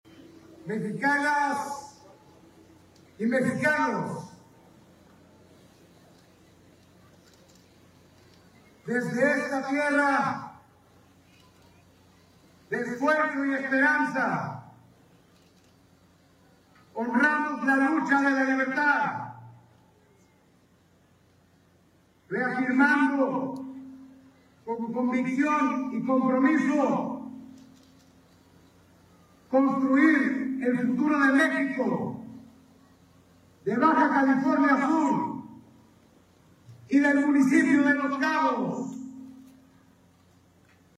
• Con gran fervor y civismo, familias de Los Cabos vitorearon a los héroes y heroínas que nos dieron Patria
Los Cabos, Baja California Sur | 16 de septiembre del 2025.- Cientos de familias celebraron el 215° Aniversario de la Independencia de México, en una ceremonia solemne encabezada por el alcalde de Los Cabos, Christian Agúndez Gómez, quien desde el balcón del Palacio Municipal en San José del Cabo, en compañía de la presidenta honoraria del Sistema DIF Los Cabos Sol Delgado Moreno, sus hijas y autioridades de los 3 niveles de Gobierno, recordó a los héroes y heroínas que dieron patria a la nación, al grito de «Viva México».